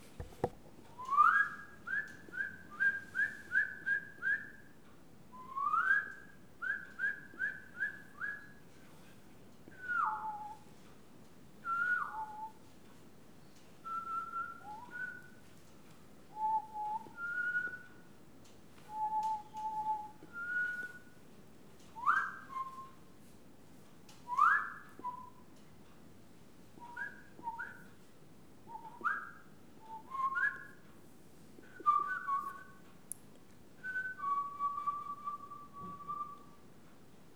Les sons ont été découpés en morceaux exploitables. 2017-04-10 17:58:57 +02:00 6.3 MiB Raw History
sifflement-oiseau_02.wav